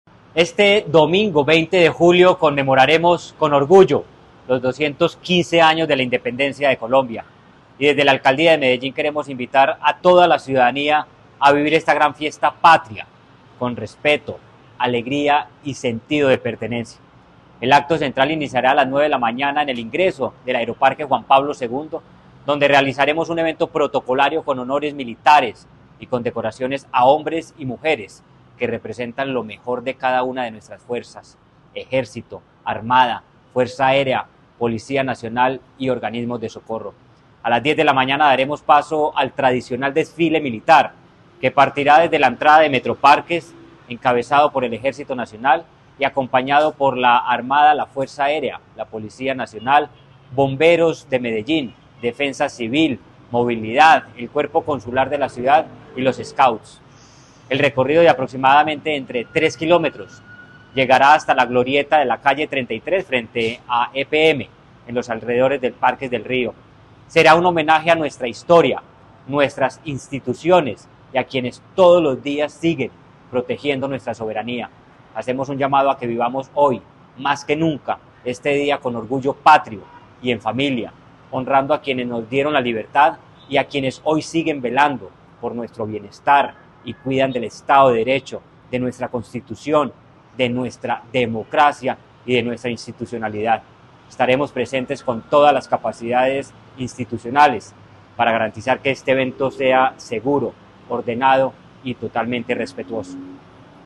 Declaraciones del secretario de Seguridad y Convivencia, Manuel Villa Mejía Este domingo, 20 de julio, Medellín conmemorará los 215 años de la independencia de Colombia con un acto encabezado por la Fuerza Pública e instituciones civiles.
Declaraciones-del-secretario-de-Seguridad-y-Convivencia-Manuel-Villa-Mejia-2.mp3